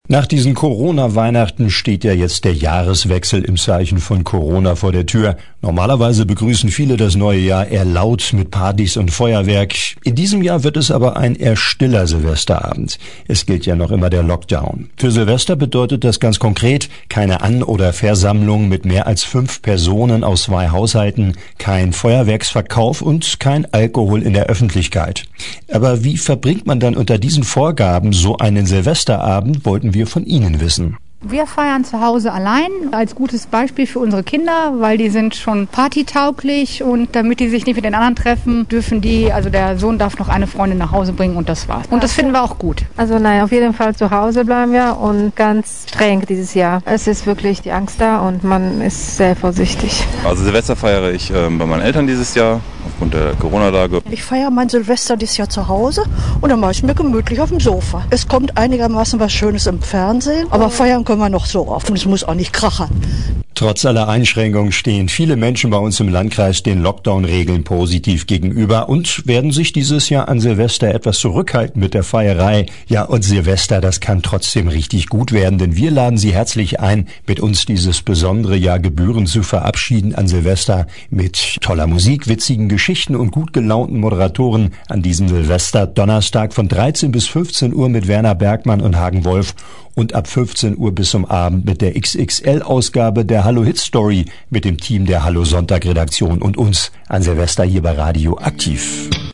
Landkreis Hameln-Pyrmont: UMFRAGE SILVESTER
landkreis-hameln-pyrmont-umfrage-silvester.mp3